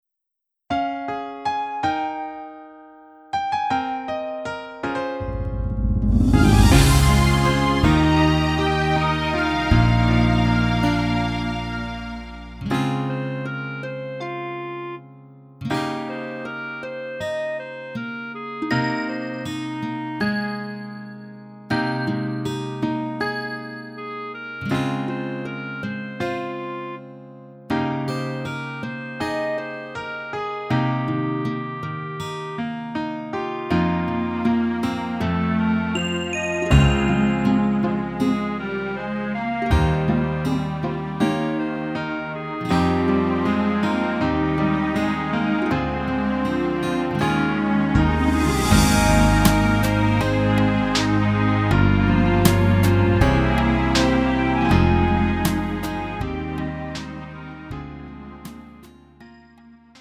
음정 원키 4:15
장르 가요 구분 Lite MR